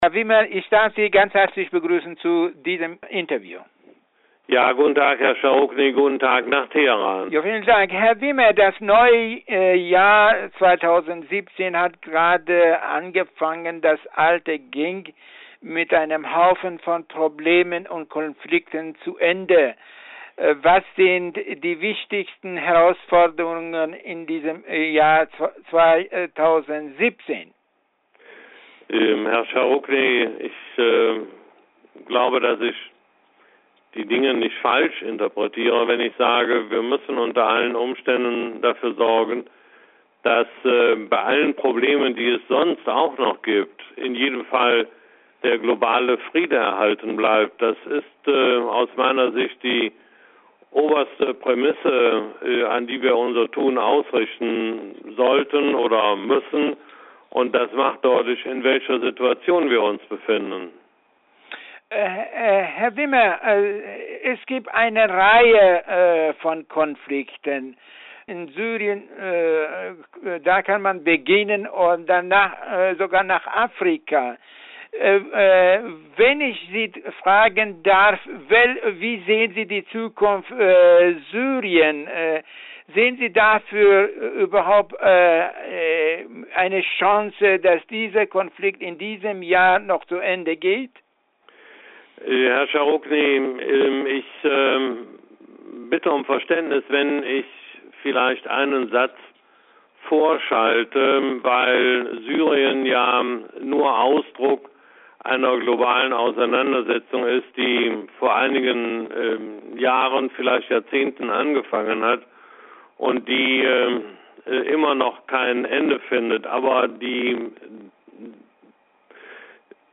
Interview mit Willy Wimmer